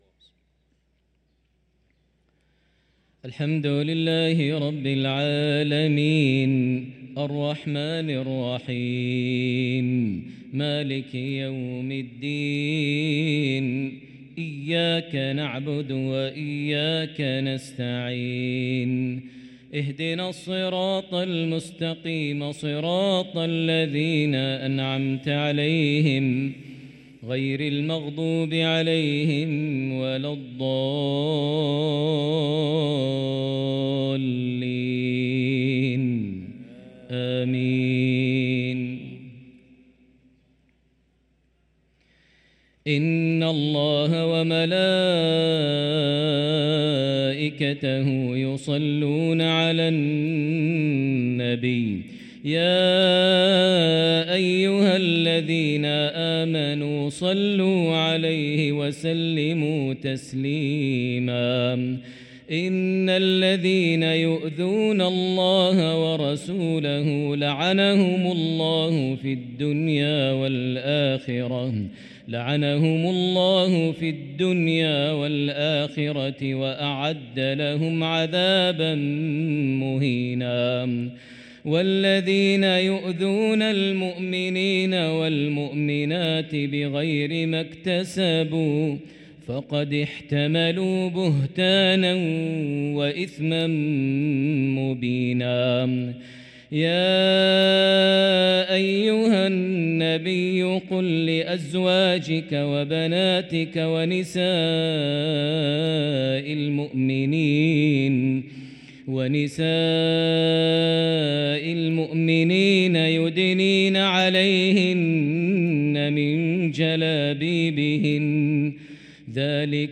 صلاة العشاء للقارئ ماهر المعيقلي 20 ربيع الأول 1445 هـ
تِلَاوَات الْحَرَمَيْن .